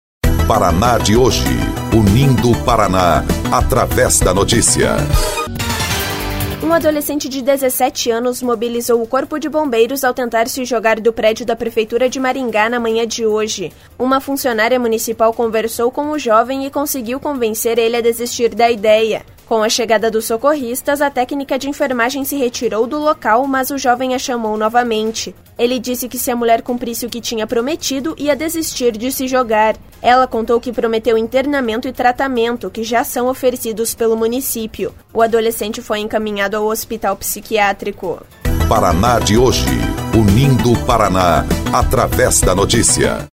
BOLETIM – Adolescente tenta se jogar do prédio da Prefeitura de Maringá